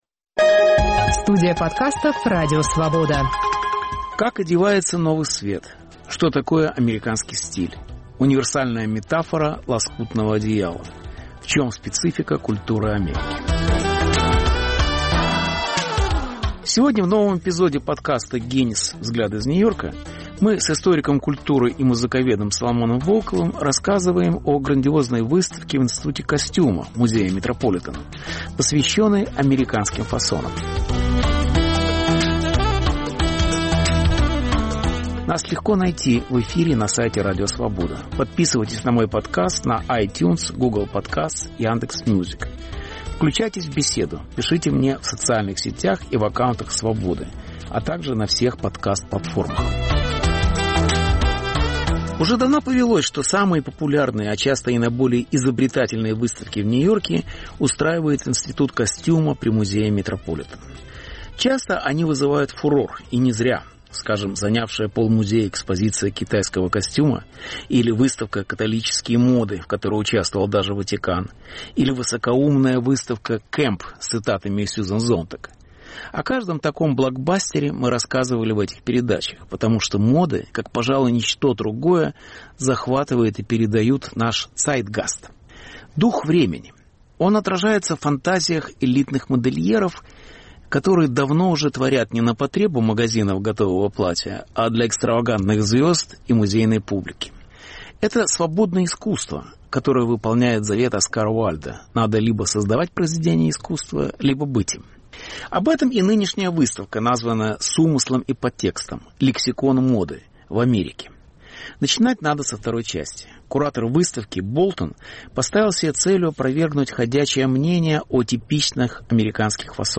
Беседа с Соломоном Волковым об американском стиле в моде - и в культуре